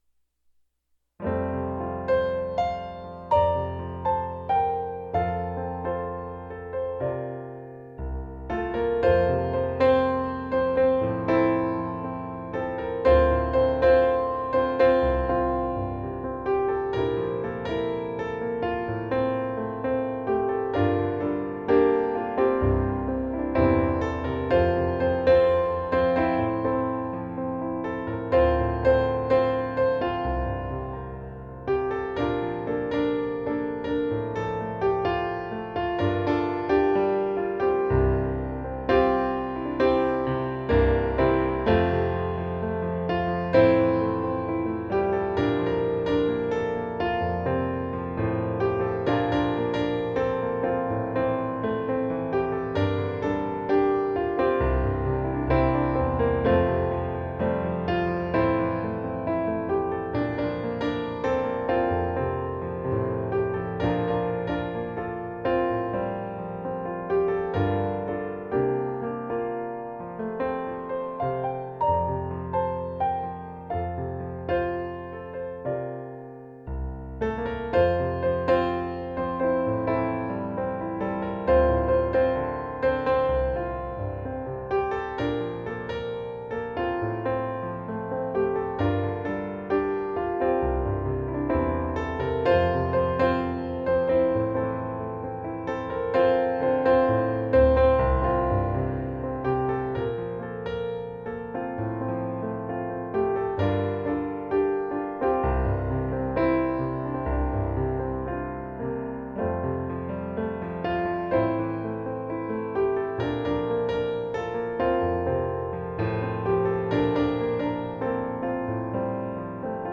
Piano & Voice